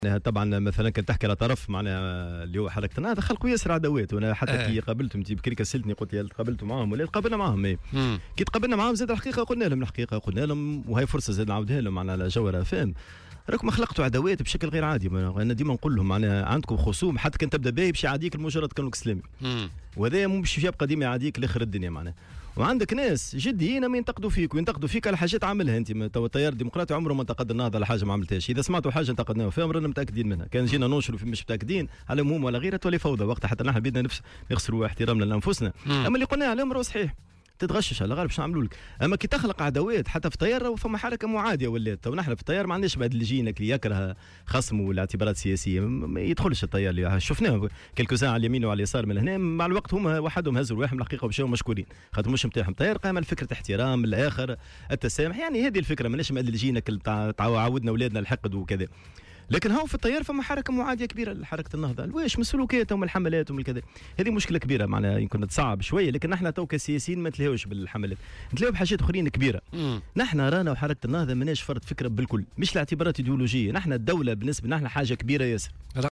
وأضاف عبو، ضيف برنامج "بولييتكا" اليوم على "الجوهرة أف أم" :على الرغم من ذلك إلاّ أننا فرحون في تونس بهذه الديمقراطية مقارنة بما يوجد في الدول العربية الأخرى".